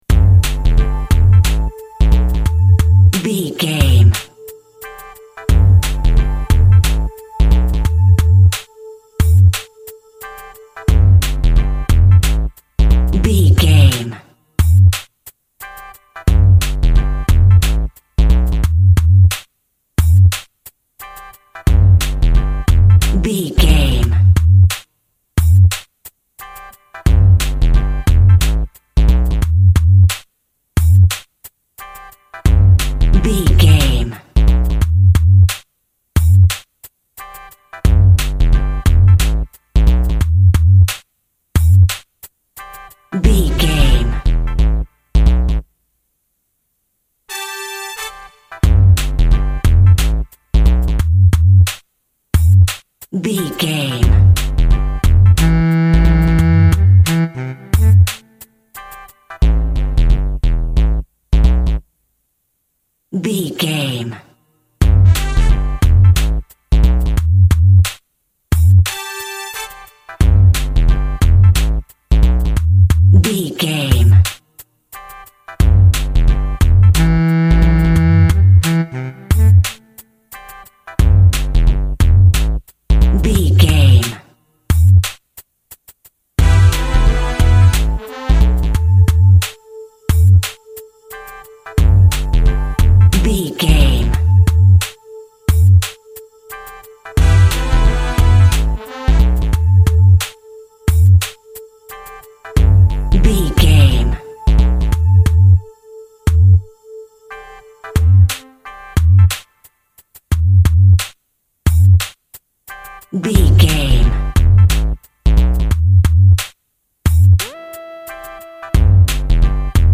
Urban Pop.
Ionian/Major
hip hop
synth lead
synth bass
hip hop synths